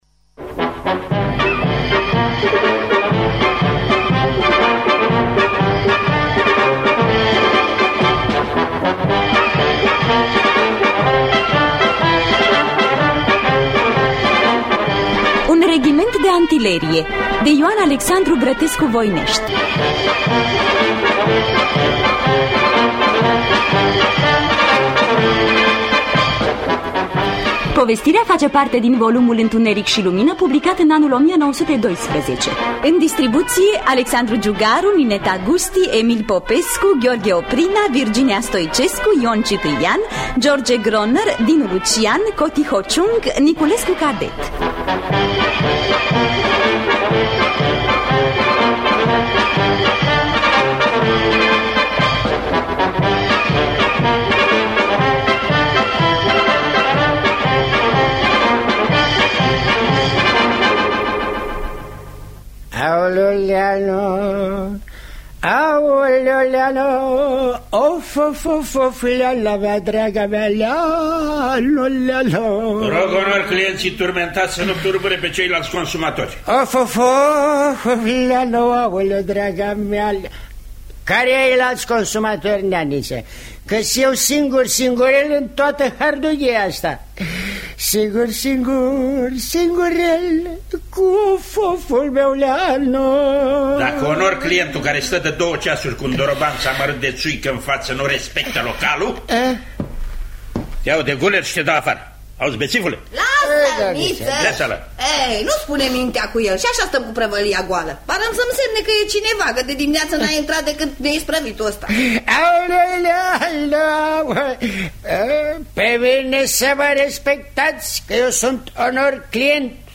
Un reghiment de antilerie de I. Al. Brătescu-Voineşti – Teatru Radiofonic Online